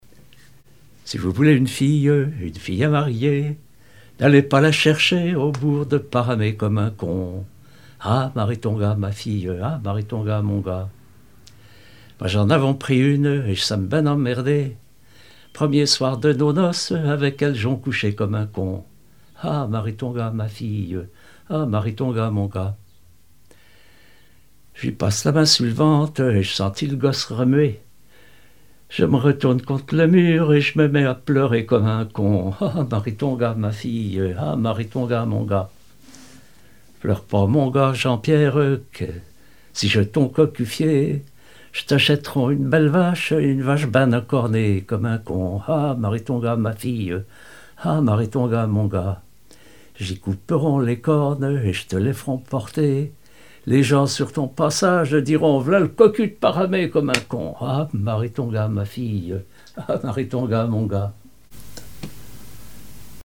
chansons maritimes et paillardes
Pièce musicale inédite